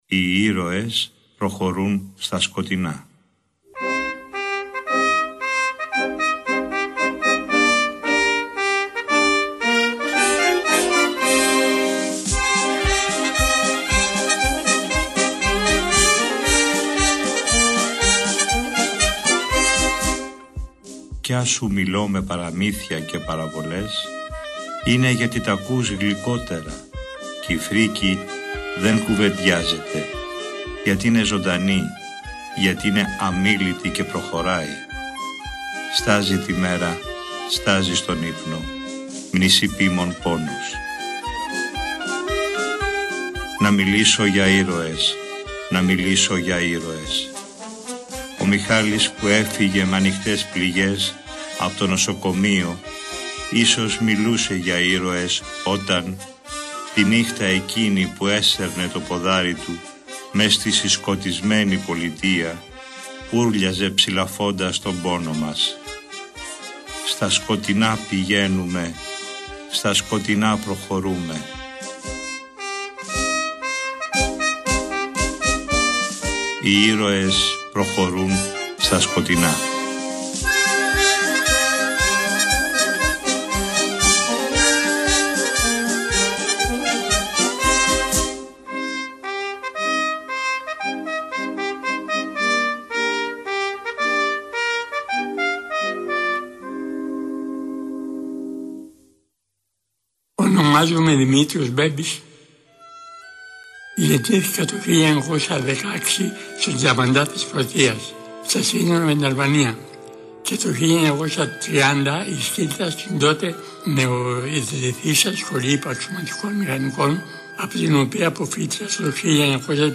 Η εκπομπή αυτή μεταδόθηκε την Κυριακή 28 Οκτωβρίου του 2012.